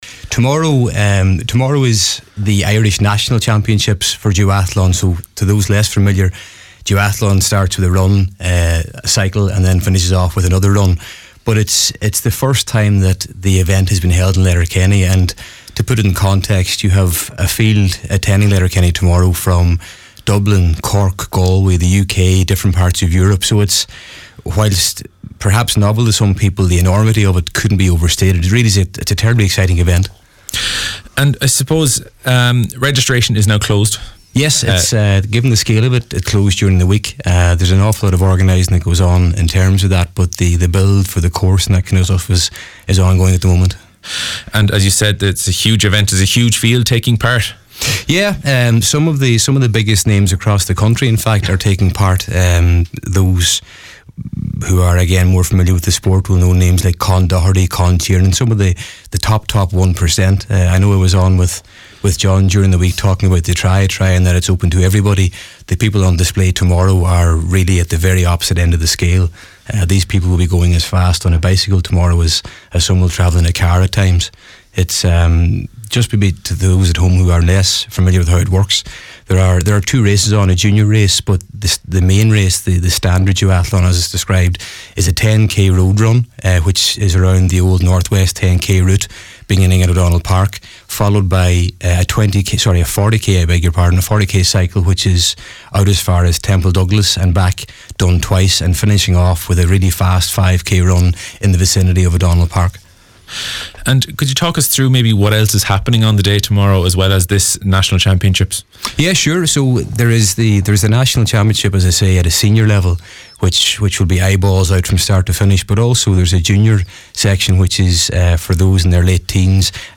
in studio, to discuss the preparations for the highly anticipated event..